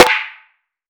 SNARE.108.NEPT.wav